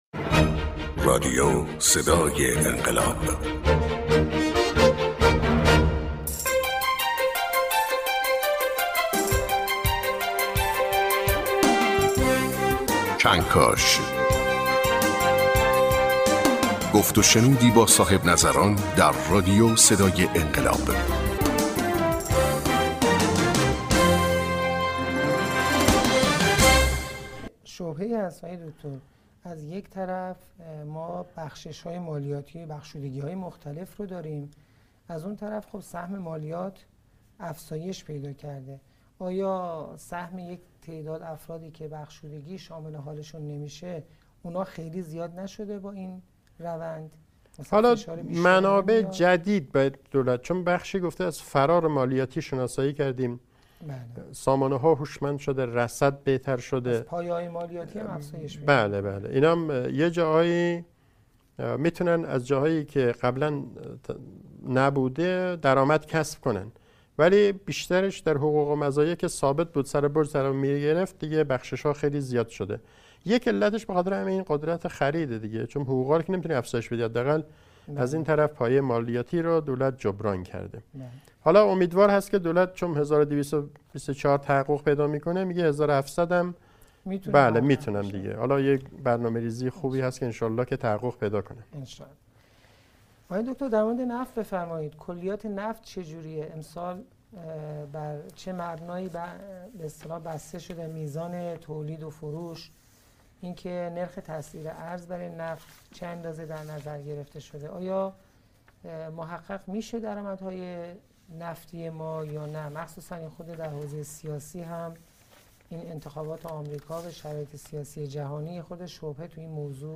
کارشناس مسائل اقتصادی